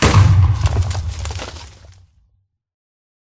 explode4.ogg